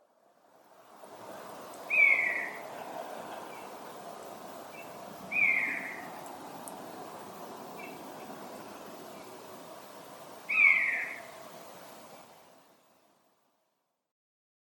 Tällä kertaa kolme hiirihaukkaa taisi olla muuttomatkalla. Nyt haukat myös kommunikoivat keskenään lyhyin, naukuviksi kuvailluin vihellyksin.
hiirihaukka.mp3